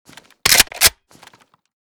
ak74_unjam.ogg.bak